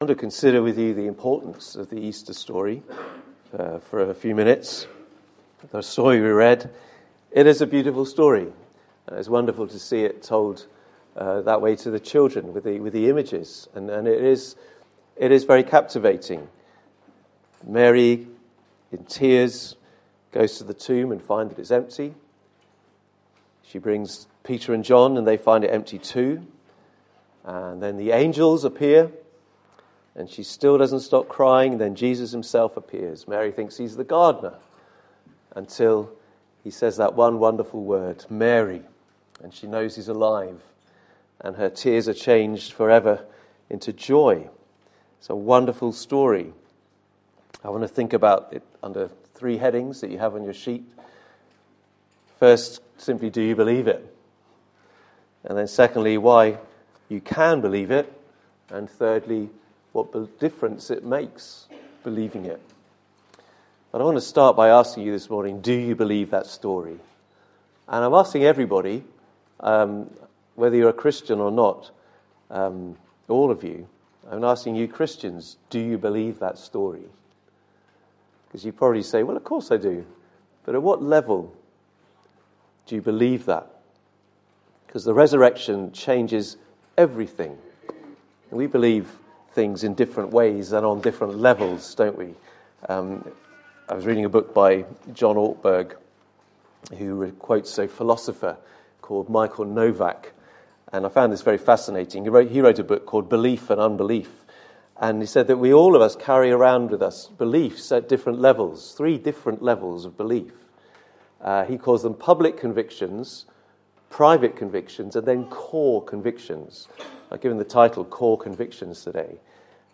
Occasional Sermons Passage: John 20:1-20 Service Type: Sunday Morning « The Fruitful Life The Risen Christ